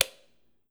click3.wav